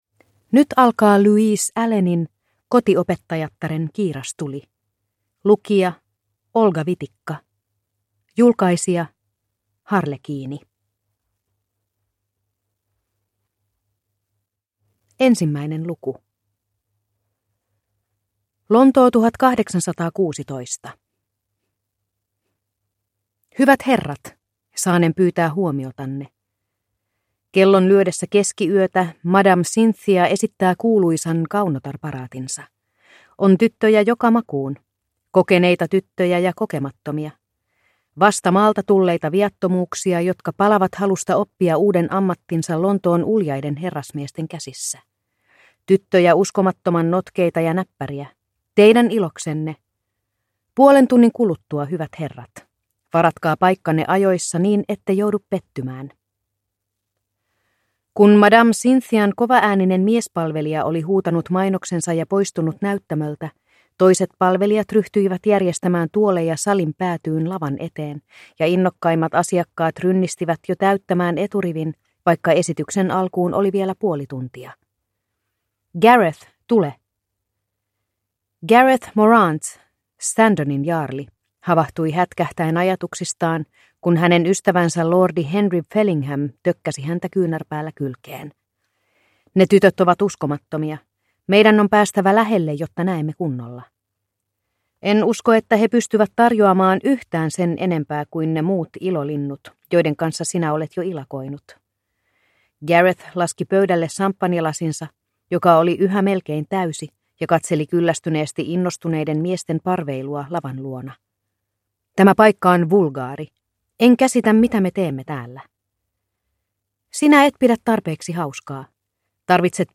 Kotiopettajattaren kiirastuli (ljudbok) av Louise Allen